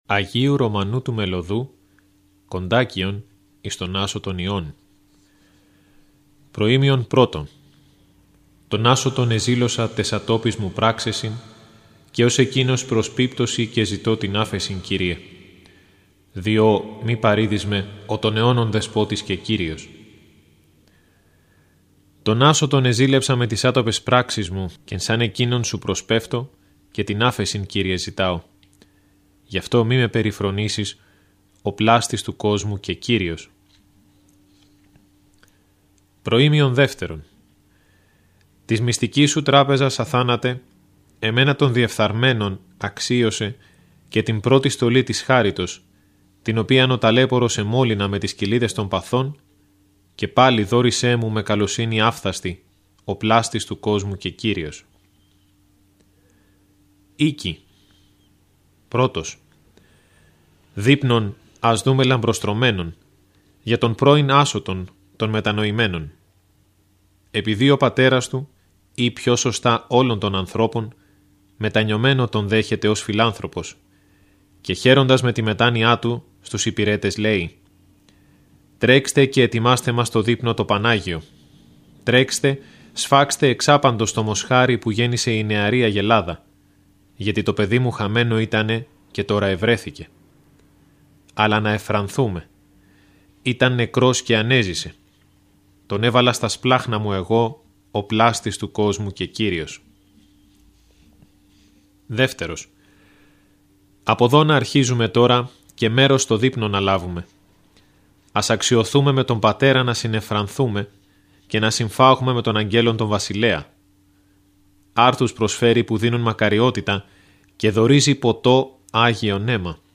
Ακούστε το επόμενο θαυμάσιο κοντάκιο του Αγίου Ρωμανού, όπως «δημοσιεύθηκε» και στο 187-ο τεύχος (Ιανουαρίου – Φεβρουαρίου του 2021) του ηχητικού περιοδικού μας, Ορθόδοξη Πορεία.